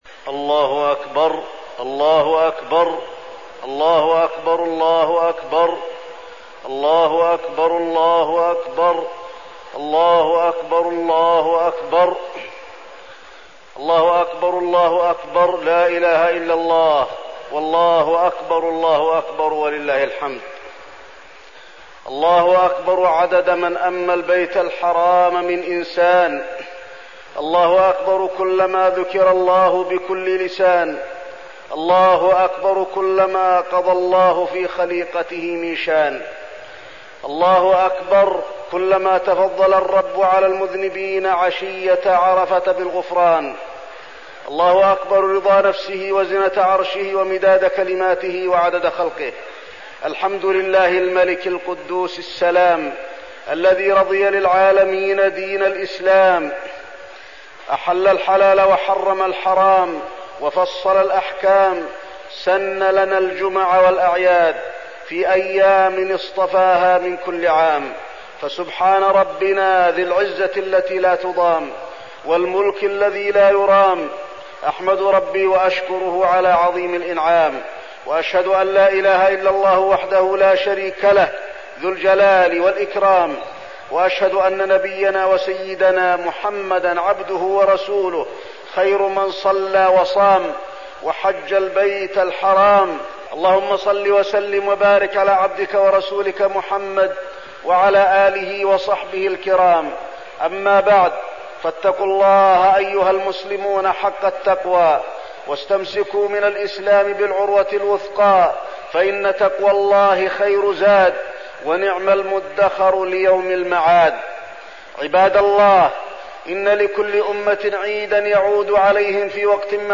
خطبة عيد الفطر - المدينة - الشيخ علي الحذيفي
تاريخ النشر ١ شوال ١٤١٨ هـ المكان: المسجد النبوي الشيخ: فضيلة الشيخ د. علي بن عبدالرحمن الحذيفي فضيلة الشيخ د. علي بن عبدالرحمن الحذيفي خطبة عيد الفطر - المدينة - الشيخ علي الحذيفي